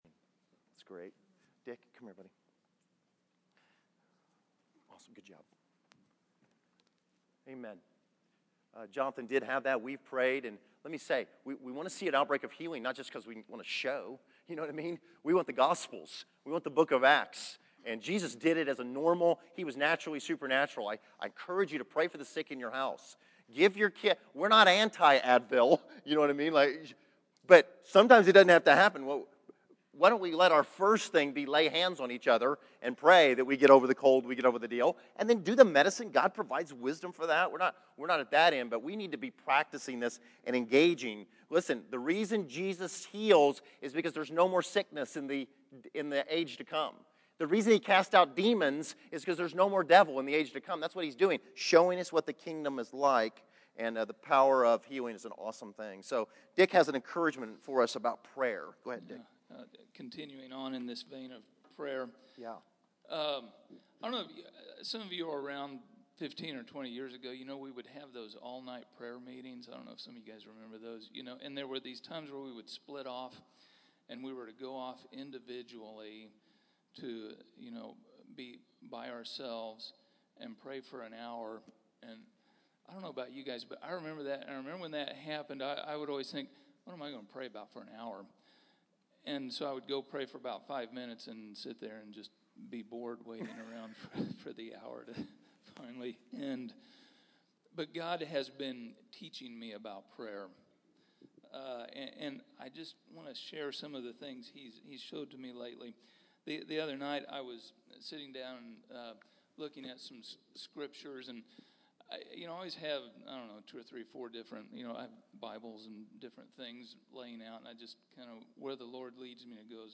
Location: El Dorado
an exhortation to prayer